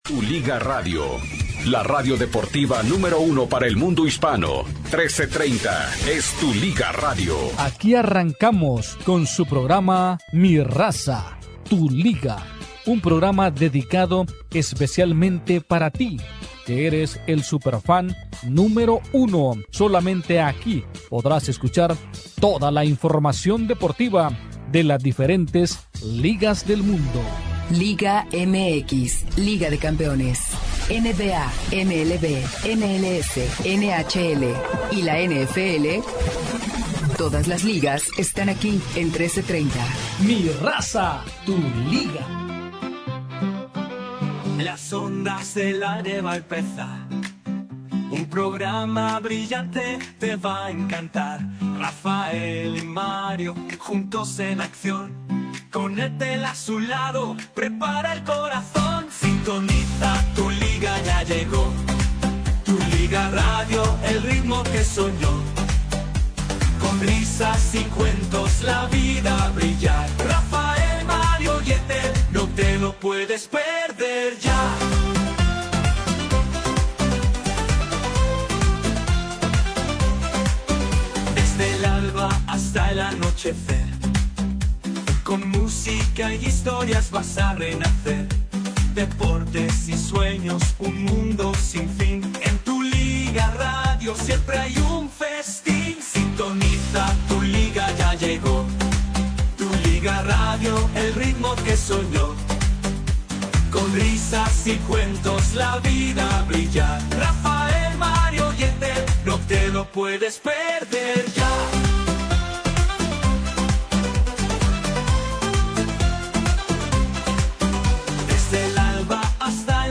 This live show is a fun mix of sports, popular culture and lifestyle.
This interactive show invites super fans to call, text, and email their opinions and be part of the show.